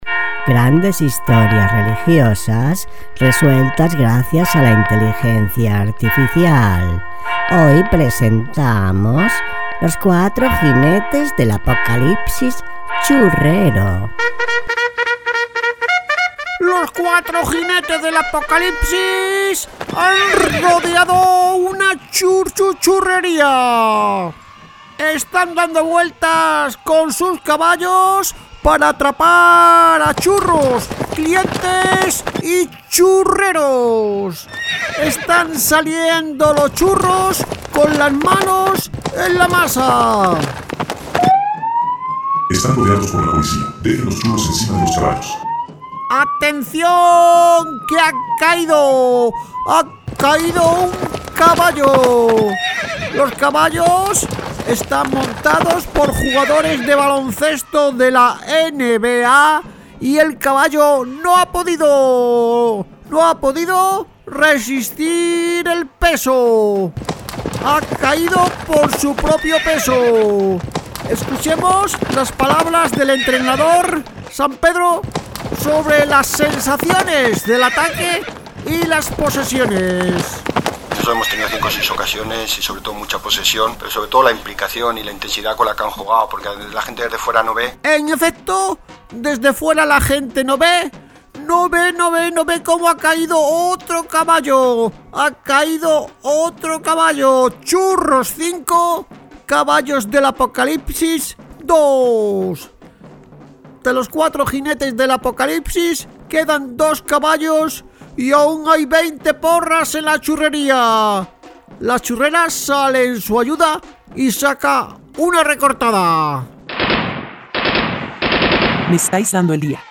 Historias de la Biblia con un poco de humor. Pódcast de humor religioso